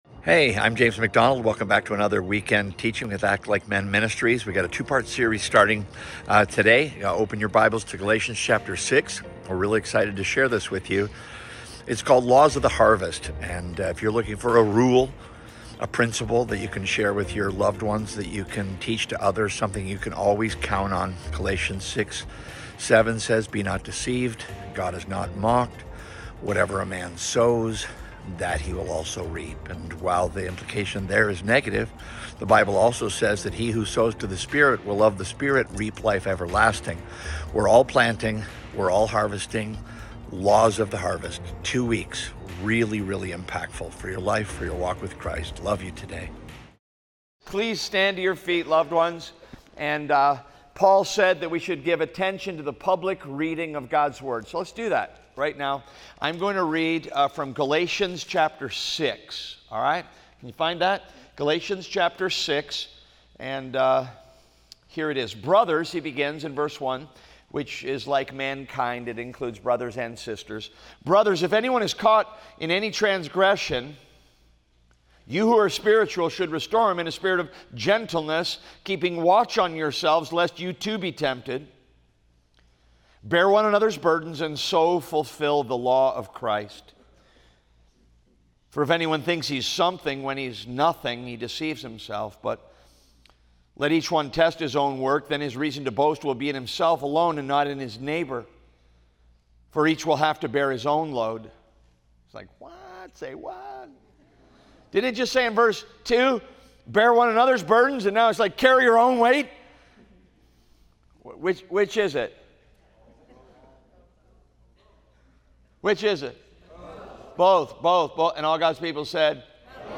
preaches without apology straight from the pages of Scripture, provoking Christians to think and act on their faith.